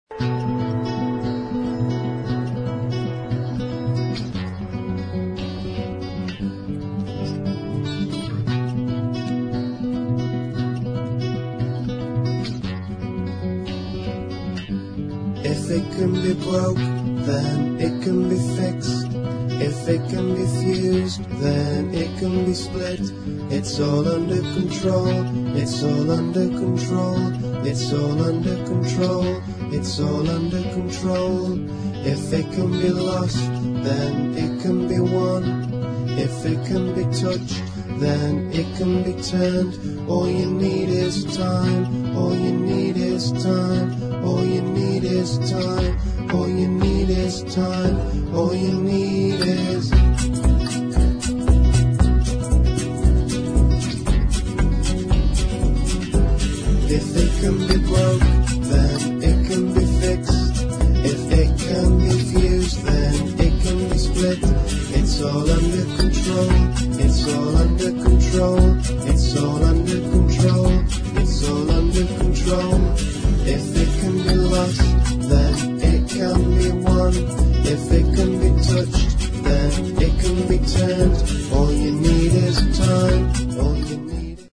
[ ELECTRONICA / INDIE ]